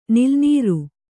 ♪ nilnīru